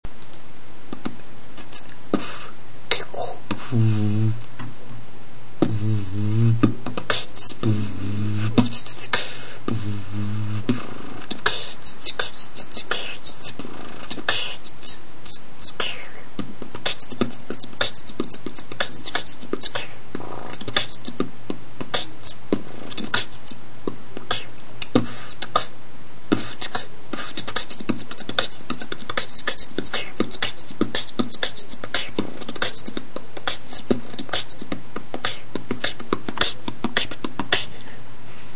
Форум российского битбокс портала » Реорганизация форума - РЕСТАВРАЦИЯ » Выкладываем видео / аудио с битбоксом » Оцените
Оцени плз бит.
для 1 месяца ритм шикарный